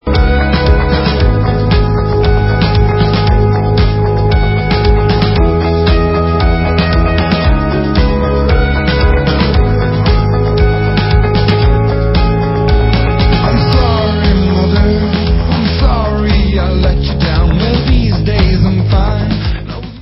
spíše energickými baladami o životě.